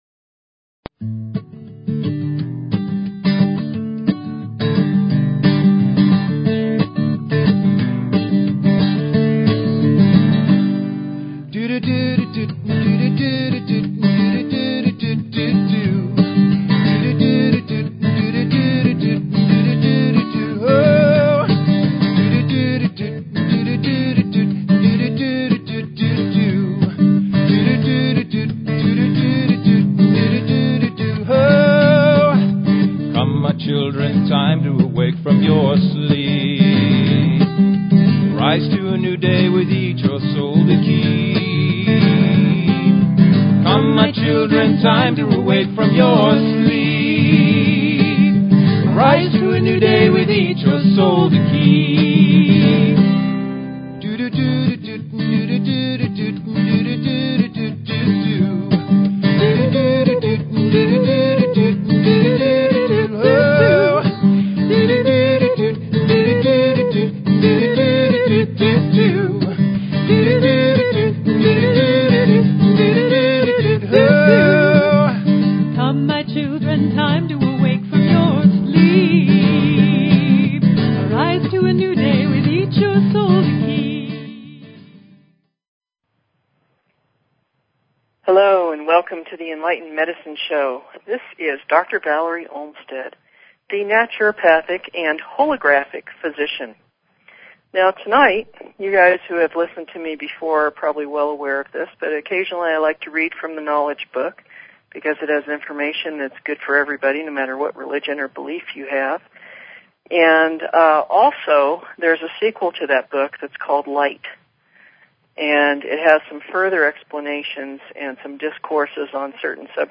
Talk Show Episode, Audio Podcast, Enlightened_Medicine and Courtesy of BBS Radio on , show guests , about , categorized as
The concepts read will be open for comment...call in with your questions and comments.